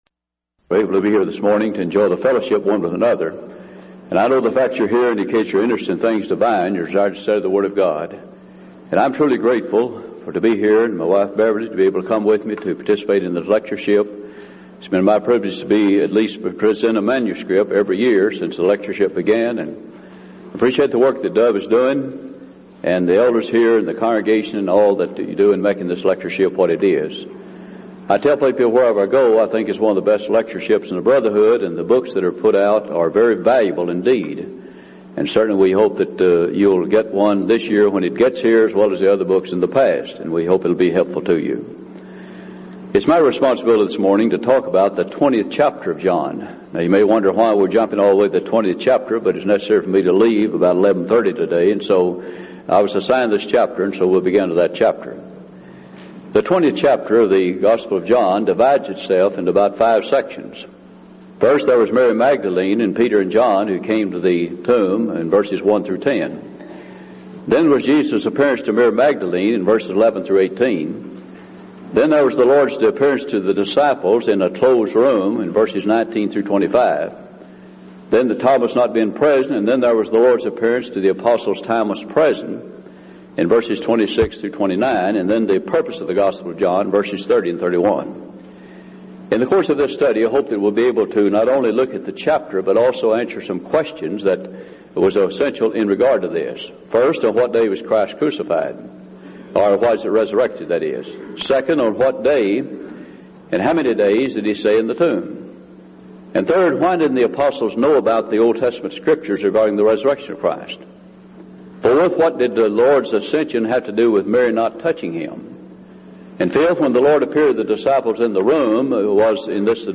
Event: 1999 Denton Lectures
lecture